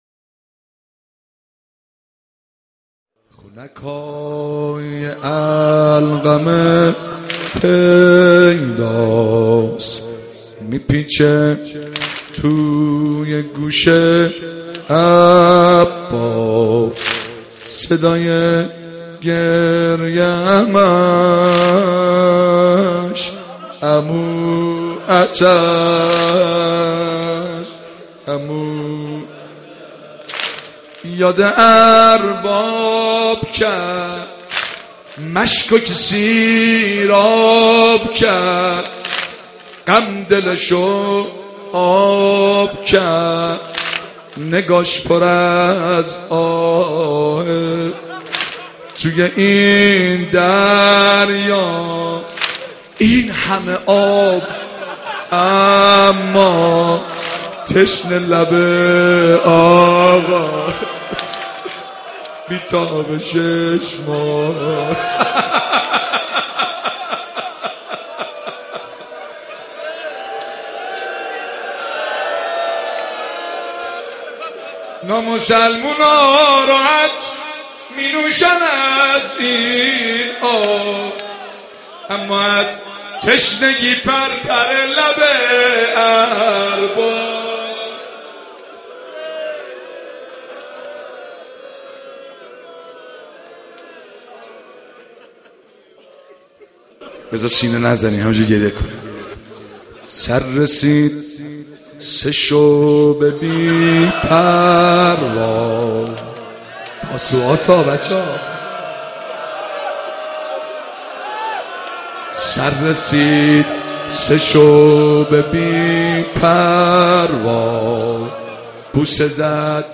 محرم 88 - سینه زنی 6
محرم-88---سینه-زنی-6